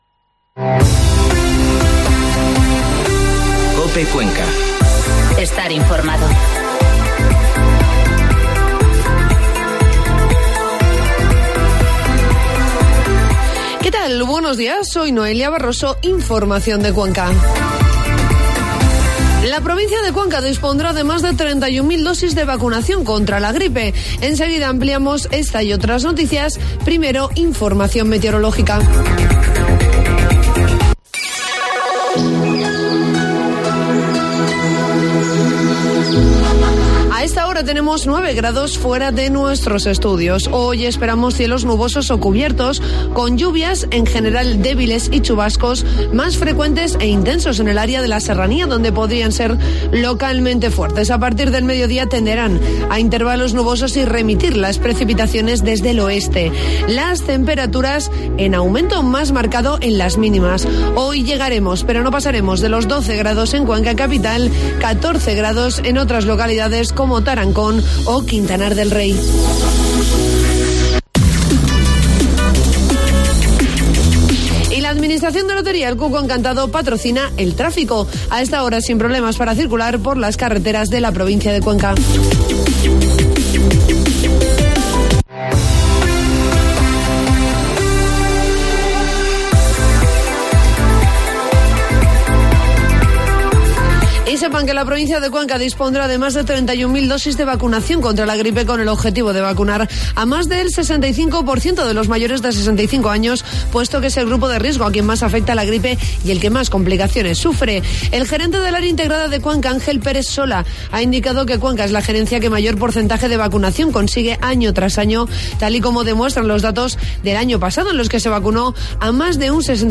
Informativo matinal COPE Cuenca 13 de noviembre